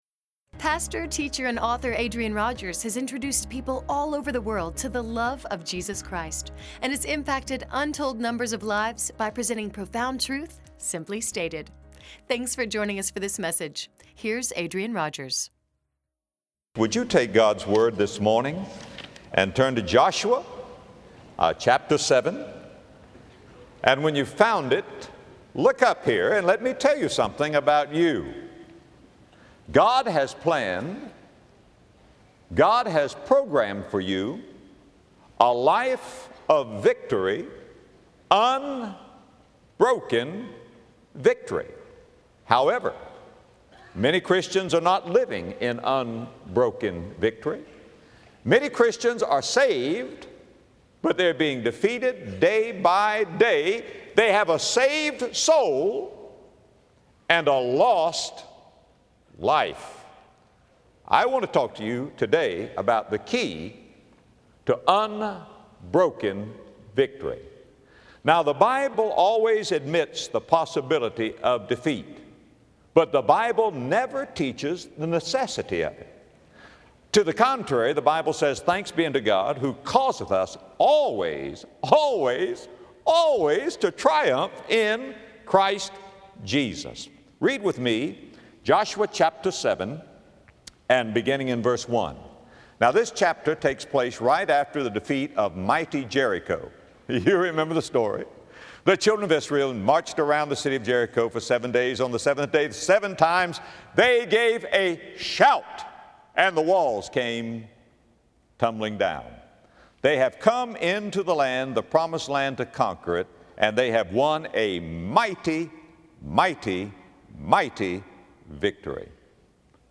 In this message from Joshua 7, Adrian Rogers reveals the pitfalls of unconfessed sin, and the key to unbroken victory.